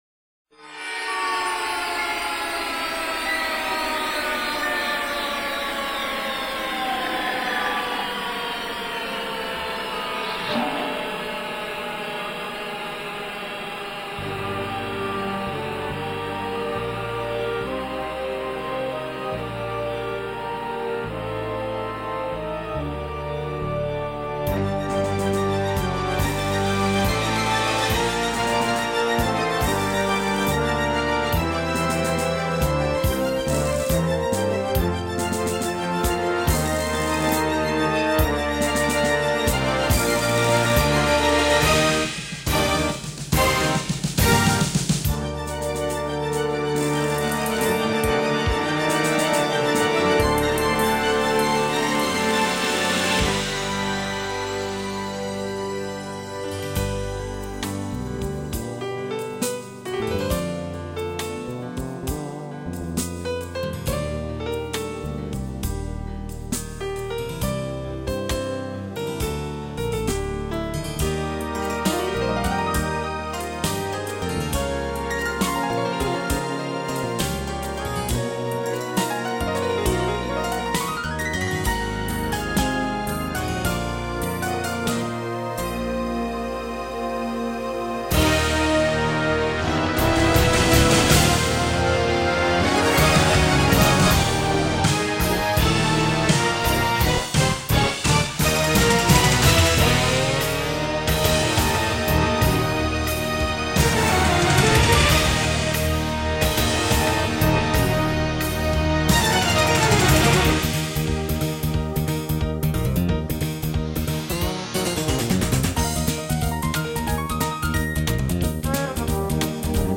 I attempted to create the same build-up type of development.